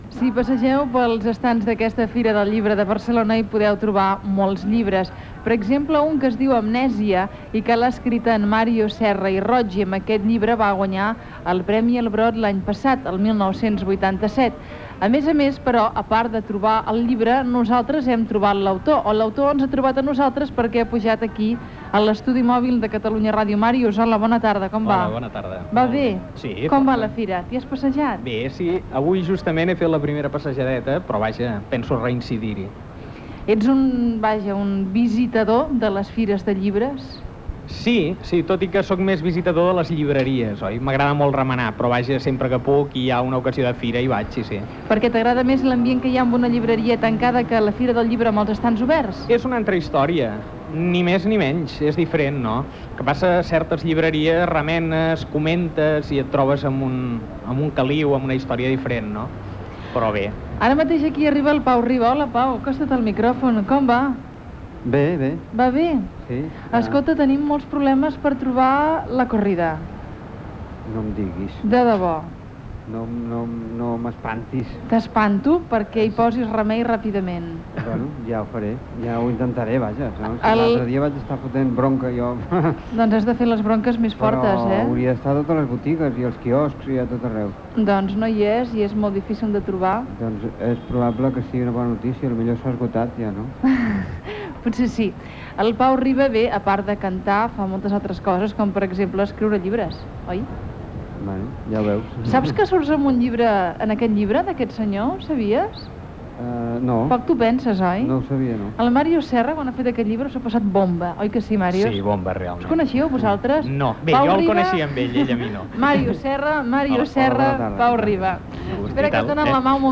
Salutació des de l'estudi mòbil de Catalunya Ràdio a la Fira del llibre de Barcelona i conversa amb Màrius Serra que presenta el seu llibre "Amnèsia". També es presenta a l'estudi Pau Riba que acaba d'escriure "La gran corrida".
Divulgació